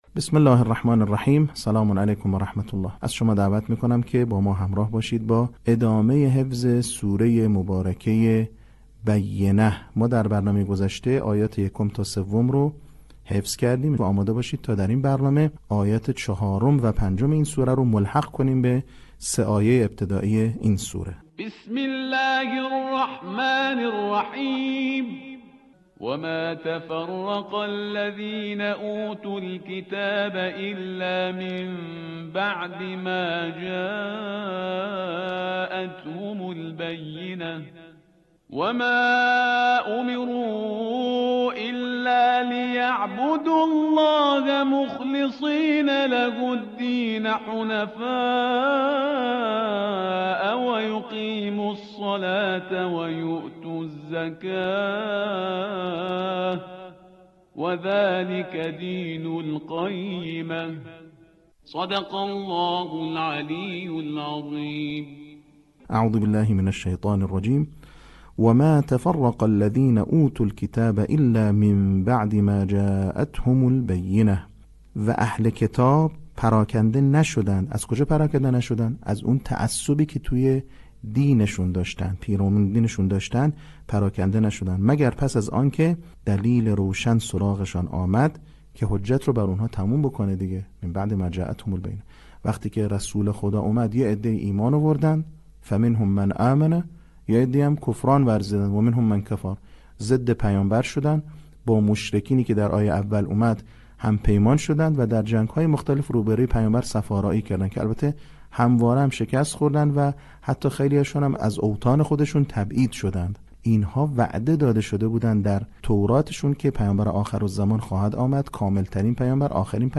صوت | بخش دوم آموزش حفظ سوره بینه